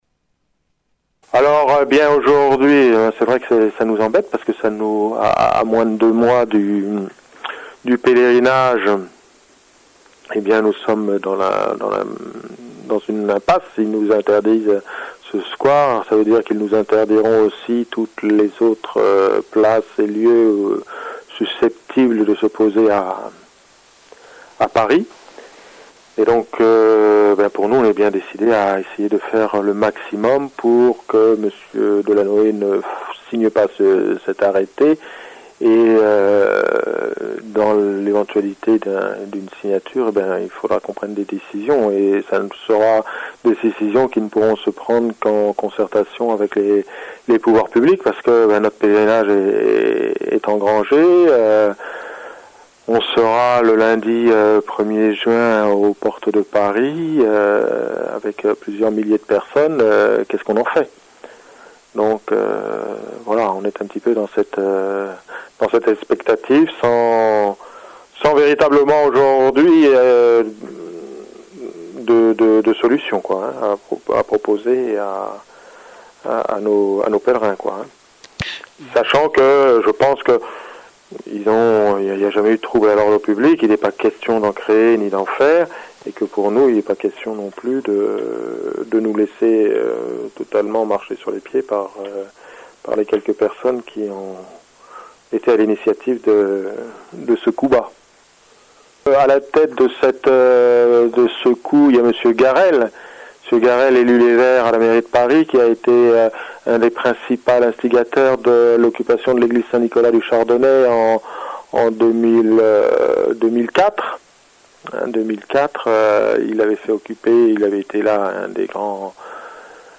Le fichier son de l’interview